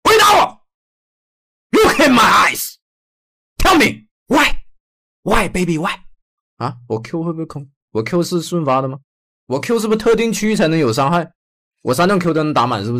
Motywacyjny lektor nauki: wzmacnianie sukcesu uczniów dzięki AI
Wykorzystaj przekonujący, autorytatywny głos AI zaprojektowany, aby inspirować skupienie, napęd i akademicką doskonałość u uczniów i osób uczących się przez całe życie.
Tekst na mowę
Narracja motywacyjna